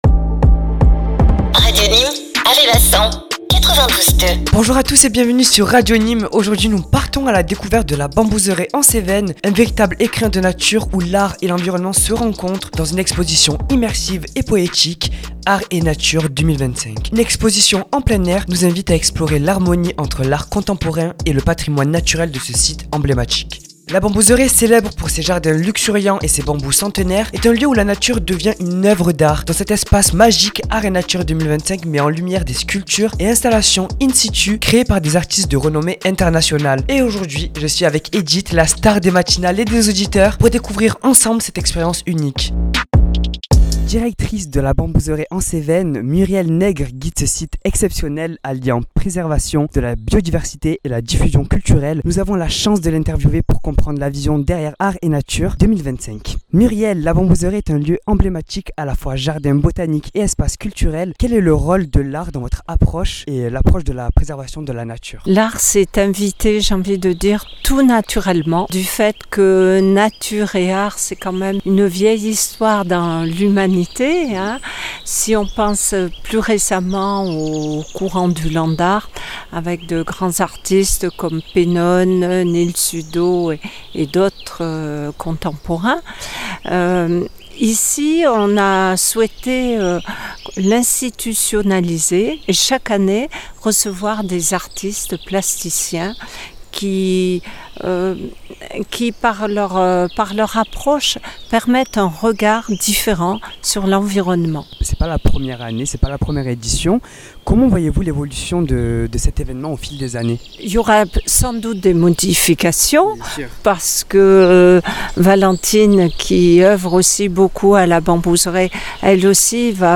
Dans ce reportage, Radio Nîmes vous emmène au cœur de La Bambouseraie en Cévennes, où se tient l’exposition Art & Nature 2025.